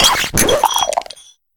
Cri de Poltchageist dans Pokémon HOME.